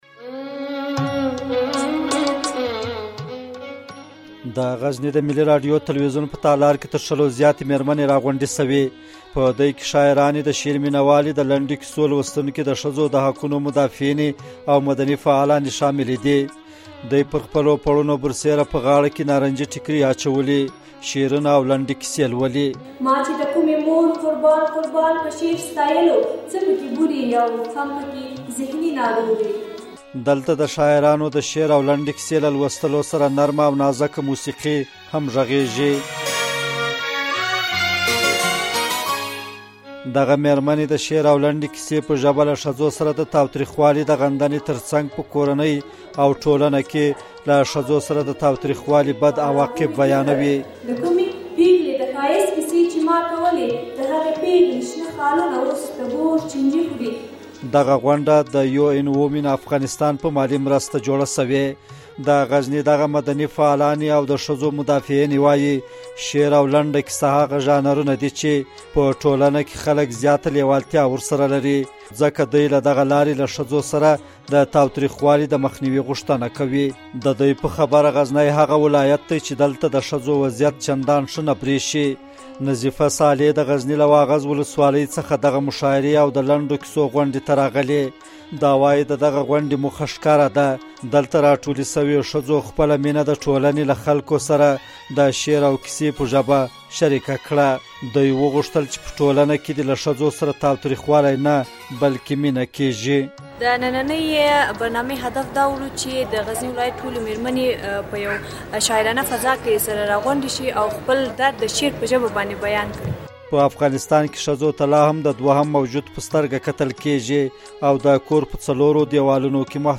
غزني راپور